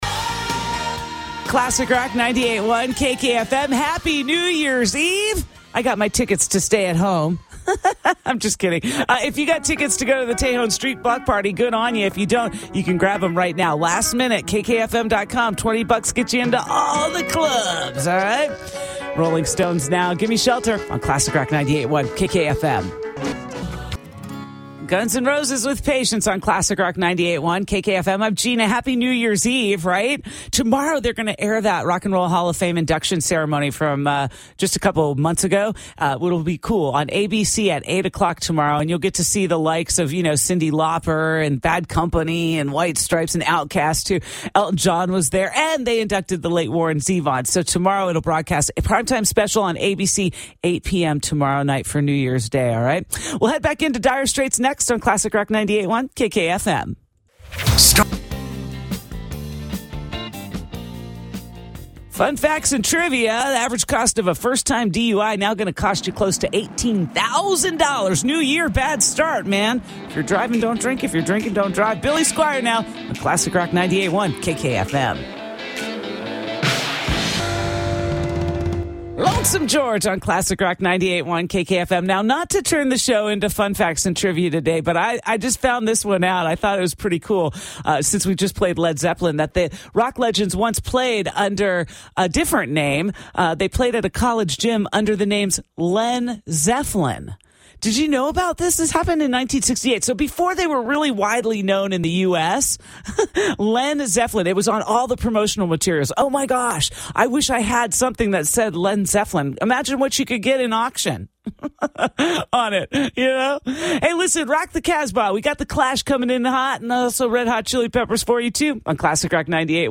Delivering commercials and other voice work locally, regionally, and nationally. Based in Atlanta with a full home studio.
Download Commercial Demo 2